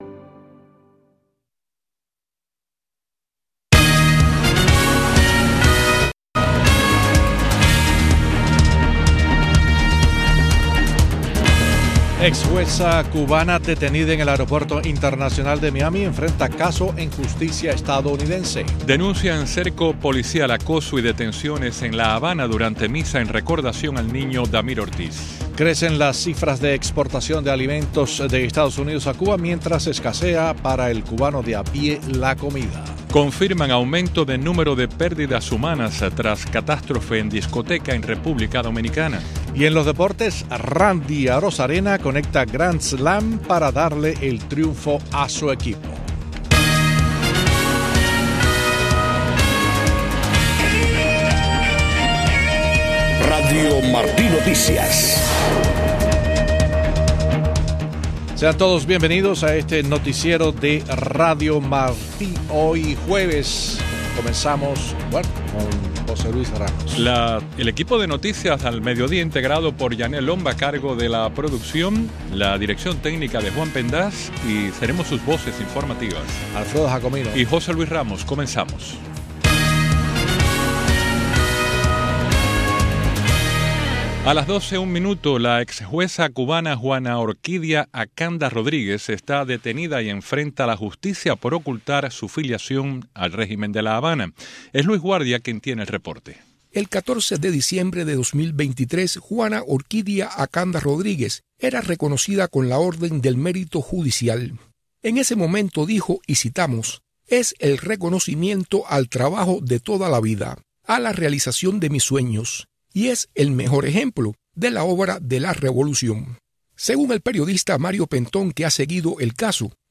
Noticiero de Radio Martí 12:00 PM